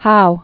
(hou)